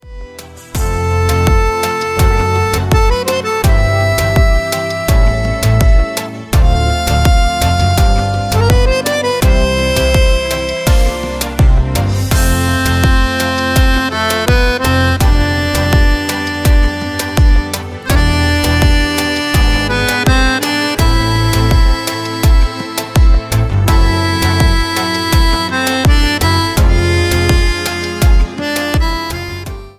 KIZOMBA  (03.40)